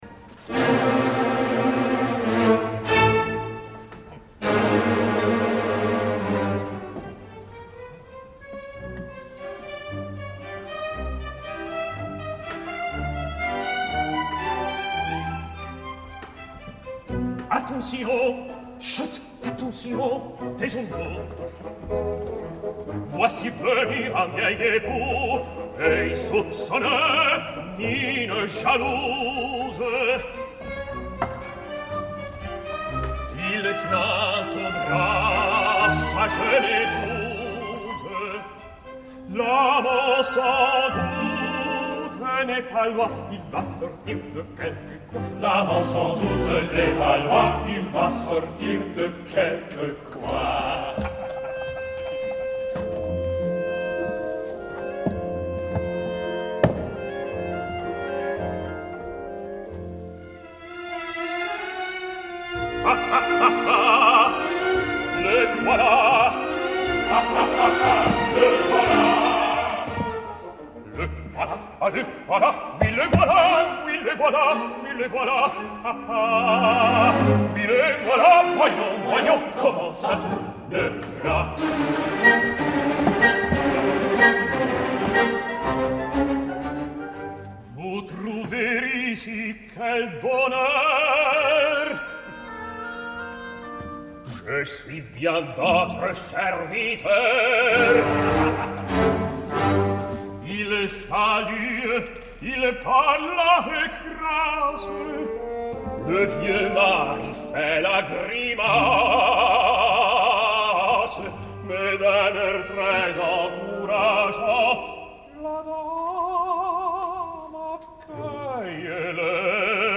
Salle Favart, l’Opéra Comique, Paris, 30 de juny de 2009